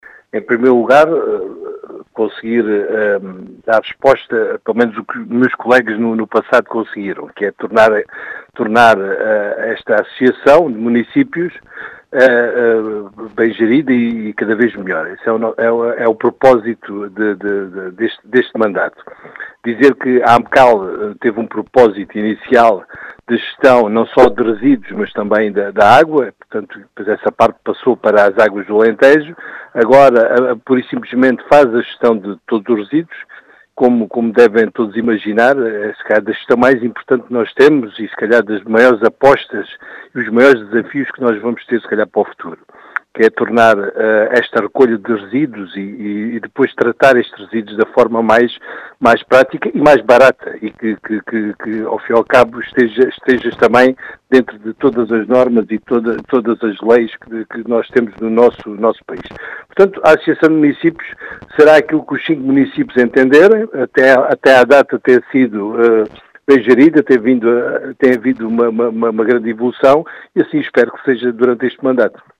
Em declarações à Rádio Vidigueira, José Efigénio, presidente da Associação de Municípios do Alentejo Central, aponta aos desafios do futuro.